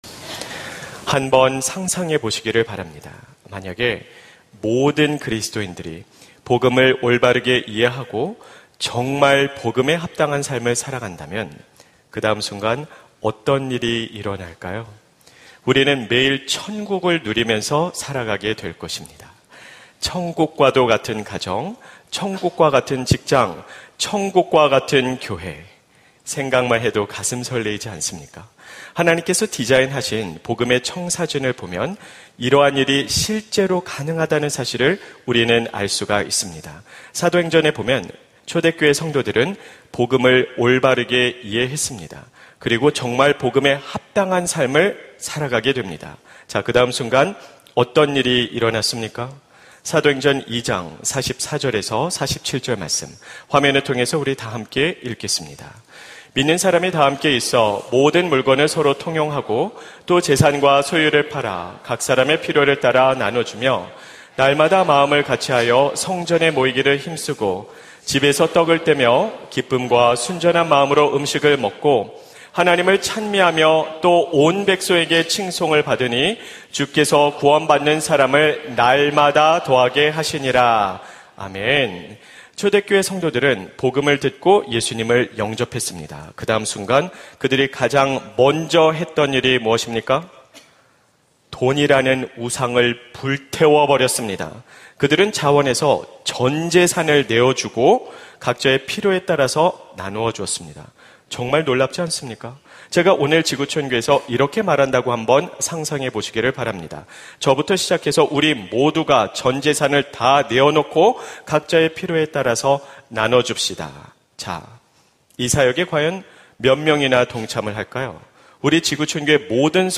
설교 : 주일예배 복음수업 - 복음수업 1 : 우리가 어쩌다 이렇게 됐을까요?